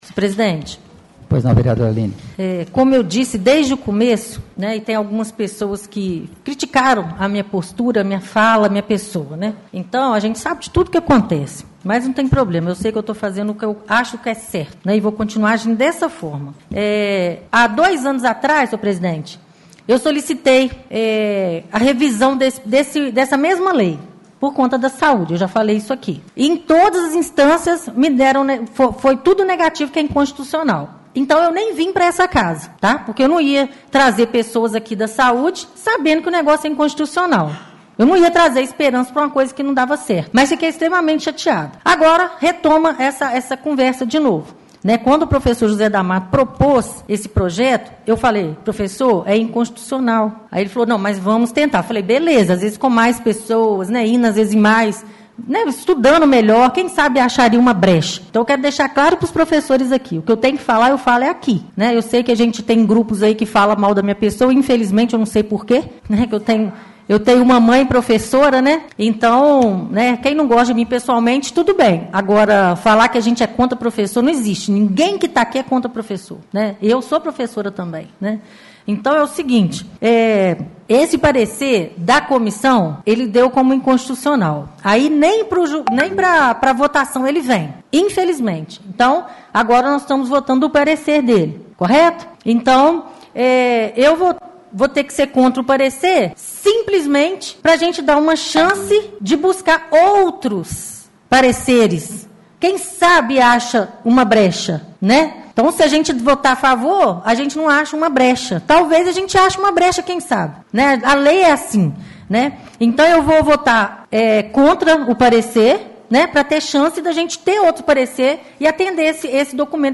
Em plenário a vereadora, Aline Mello, desabafou dizendo que pessoas criticaram sua postura